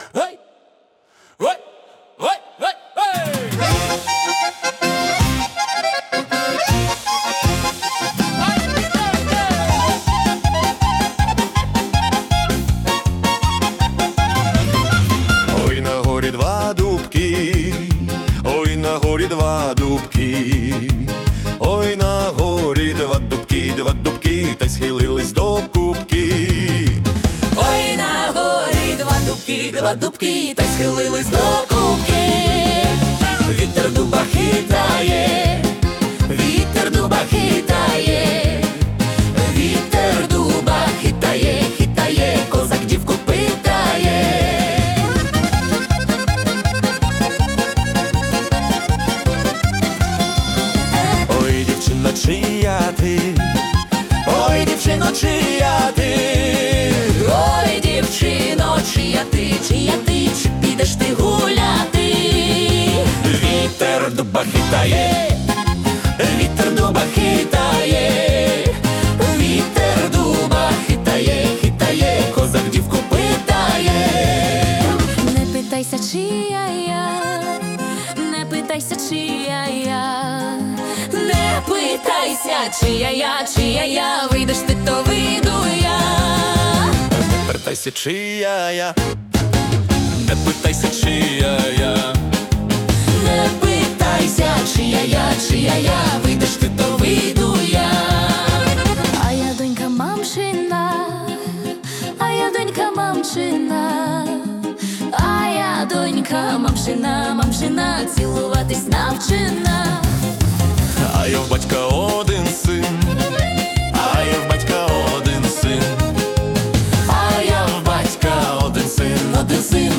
Українська полька - два дубки на горі